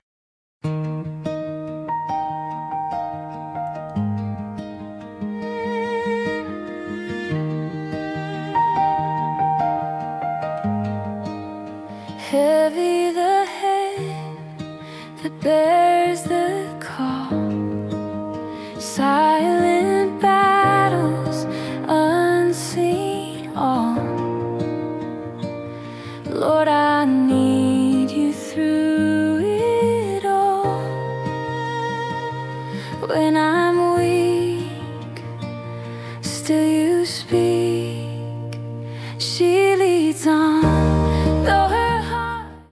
🎧 Original Worship Song + Lyrics (MP3) for prayerful focus